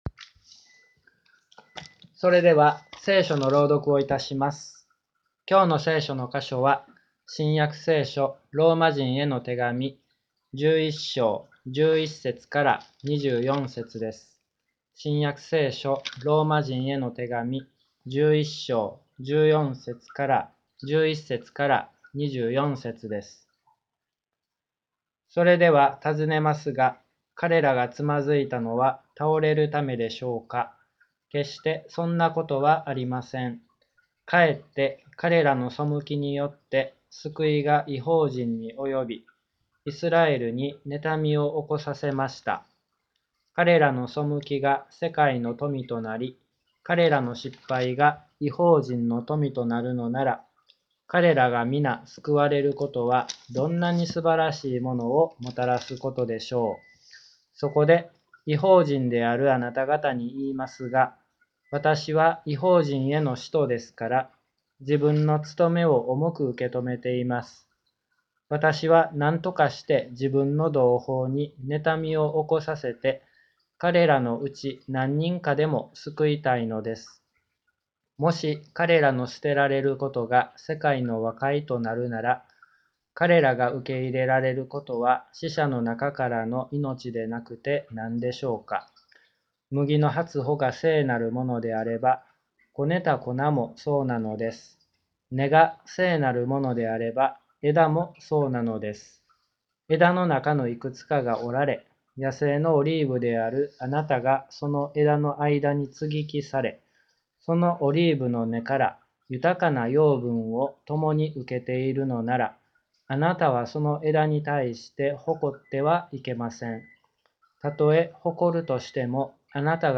礼拝説教から ２０２１年４月２５日